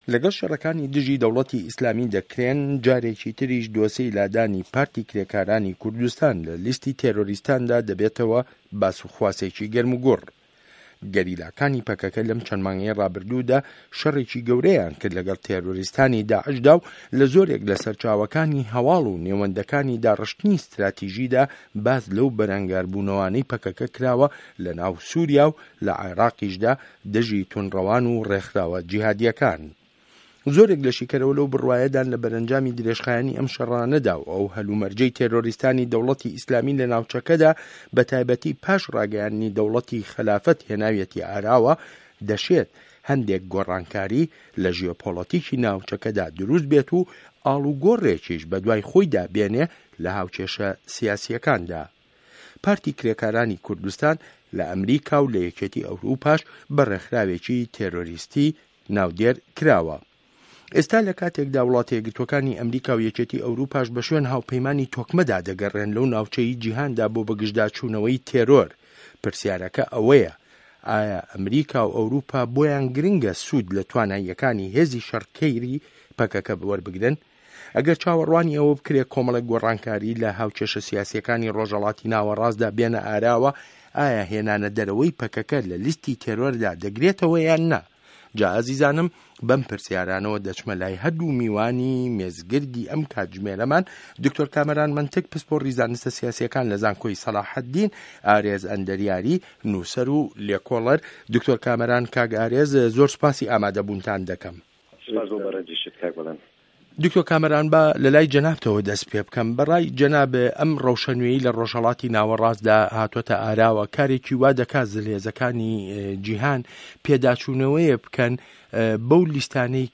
مێزگرد: داعش و دۆزی پارتی کرێکارانی کوردستان